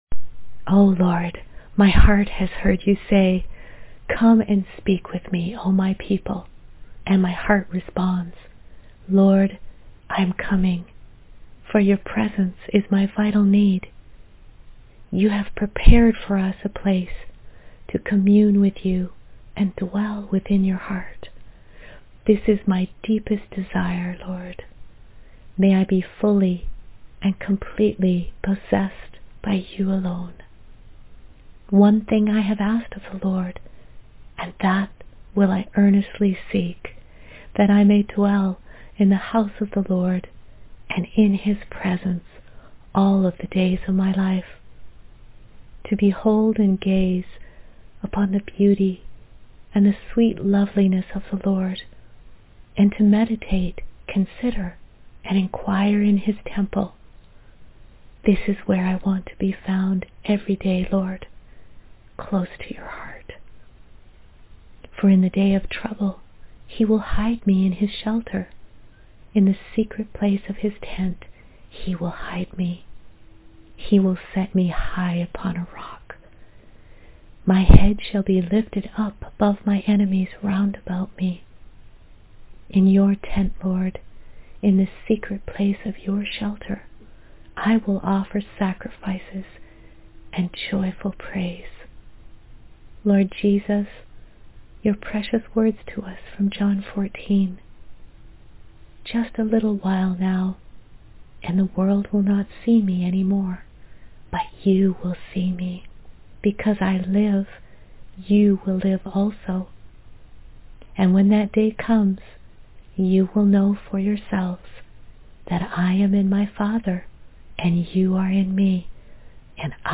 “Dwell” prayer and verse audio